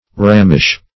rammish - definition of rammish - synonyms, pronunciation, spelling from Free Dictionary
Search Result for " rammish" : The Collaborative International Dictionary of English v.0.48: Rammish \Ram"mish\ (r[a^]m"m[i^]sh), a. Like a ram; hence, rank; lascivious.